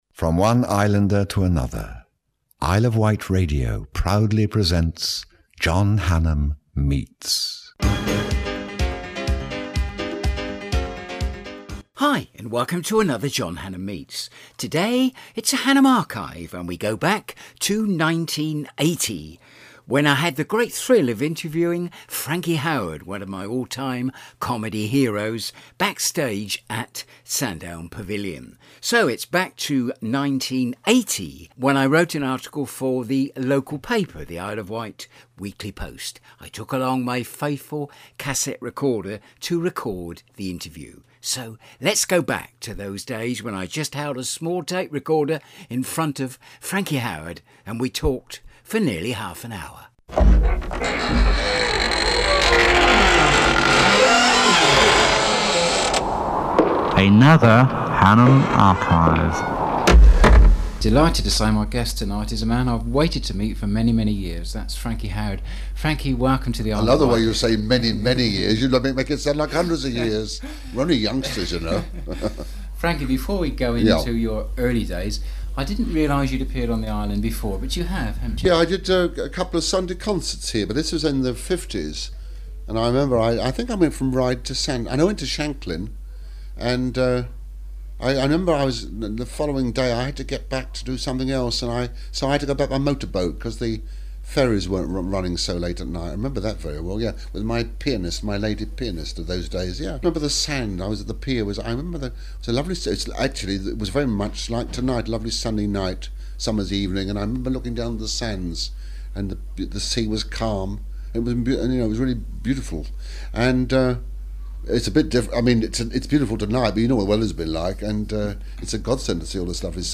interviews celebrities on their life and work